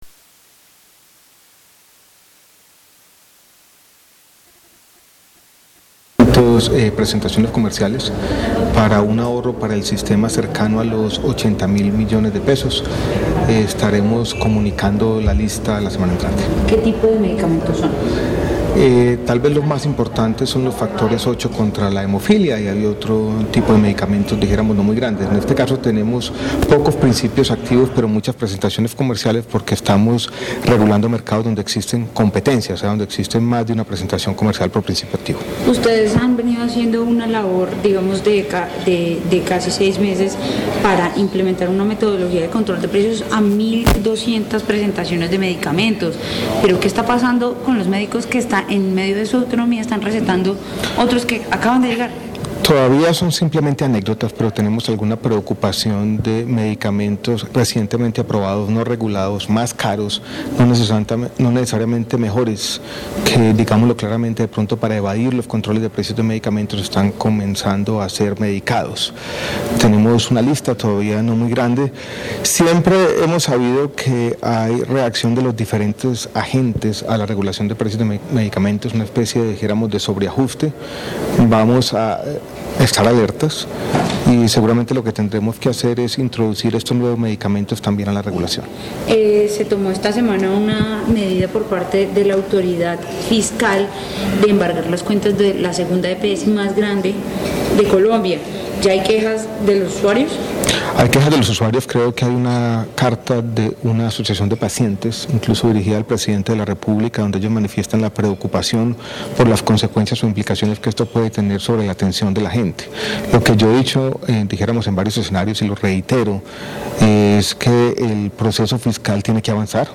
Audio, MinSalud habla sobre medidas de control a precios de medicamentos.